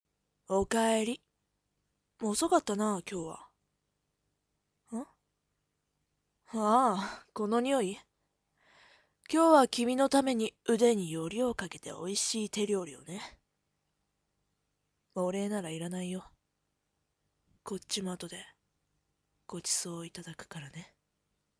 青年〜お帰り。晩御飯が手料理の理由？〜